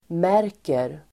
Uttal: [m'är:ker]